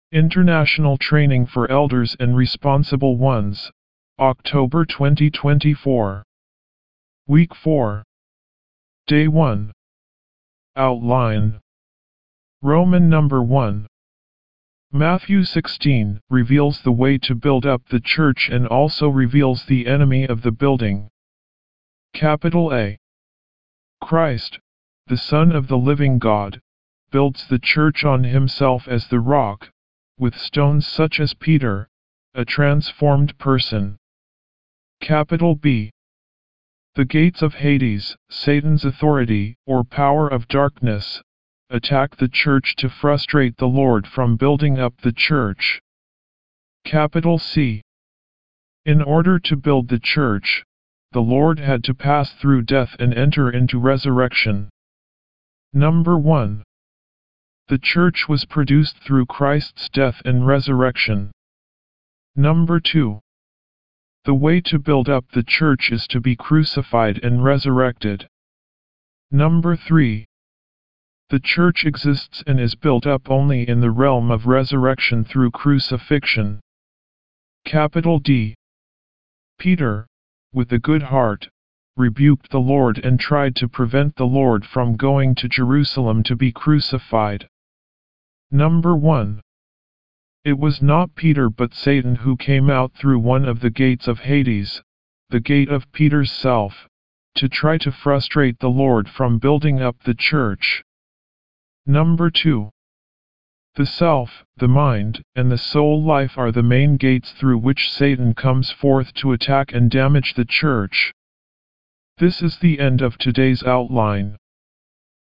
W4  Outline Recite
D1 English Rcite：